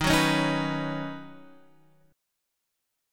D#m7#5 chord